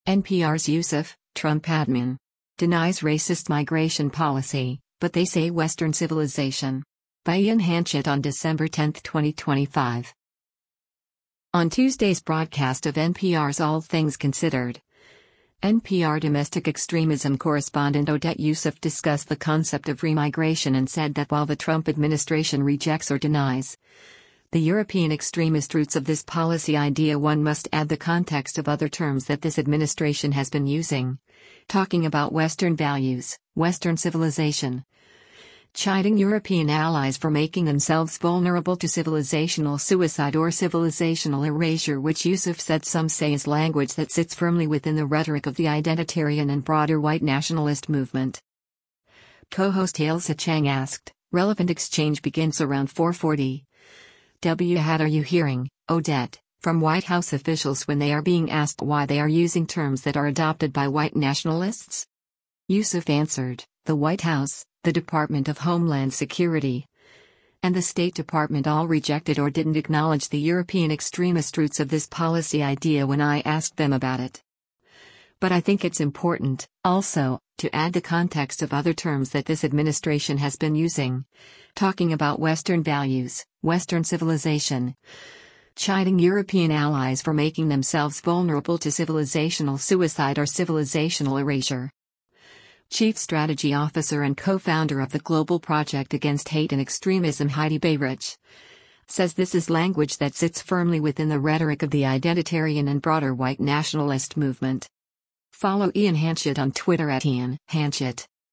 On Tuesday’s broadcast of NPR’s “All Things Considered,”